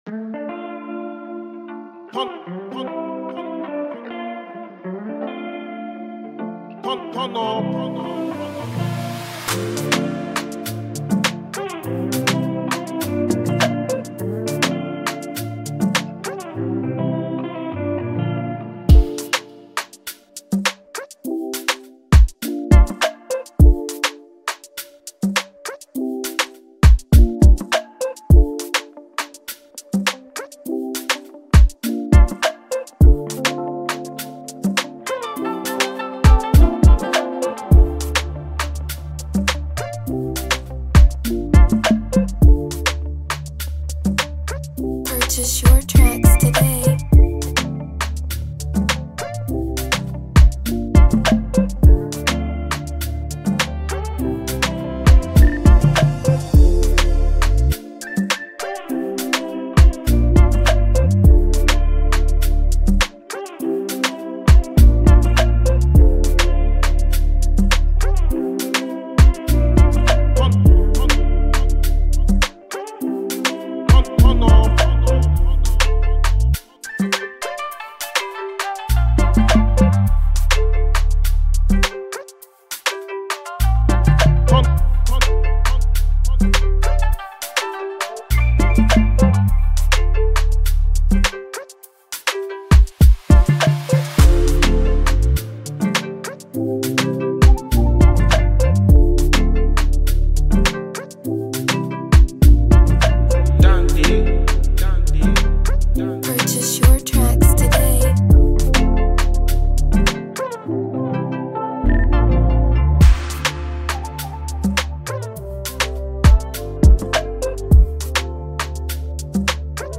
mesmerizing afrobeat instrumental